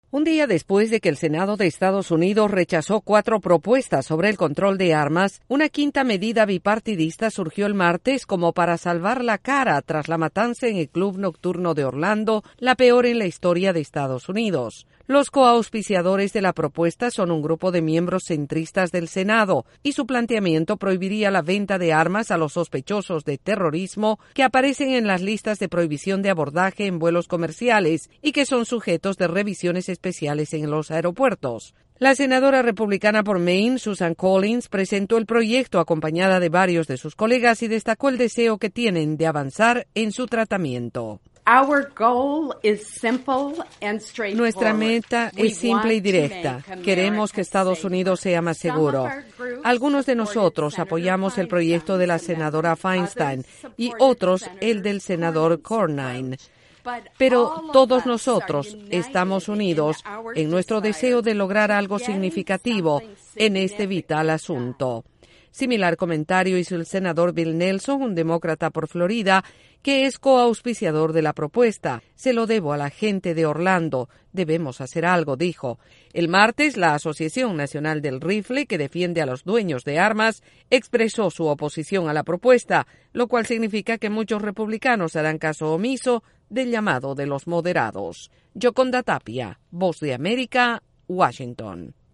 Resistiendo al fracaso en el tema del control de armas, un grupo de senadores presenta una nueva propuesta. Desde la Voz de América en Washington DC informa